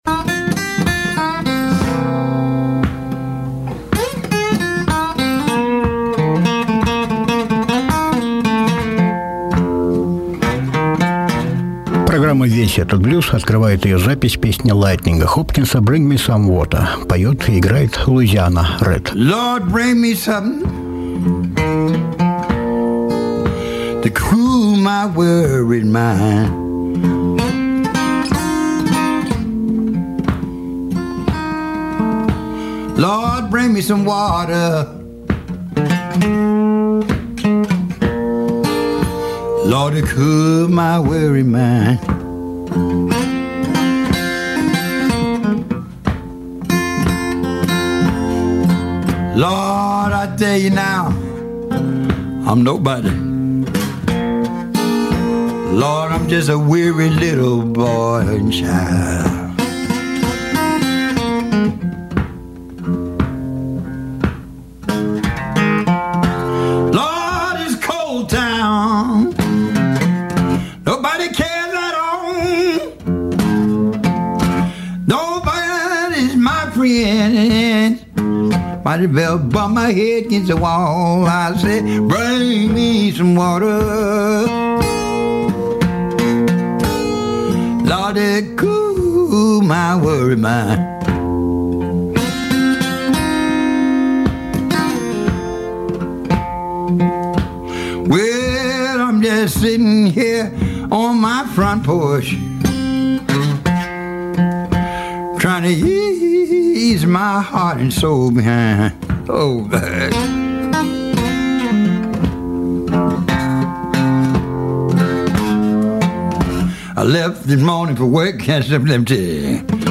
И вновь погружаемся в глубины блюза
певец, гитарист и харпер
запись джем-сейшн 6 июля 1960 г.